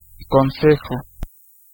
Ääntäminen
Vaihtoehtoiset kirjoitusmuodot (vanhentunut) bord Synonyymit get on get onto wainscot directorate gameboard Ääntäminen : IPA : [bɔː(r)d] Lyhenteet ja supistumat Bd (laki) Bd.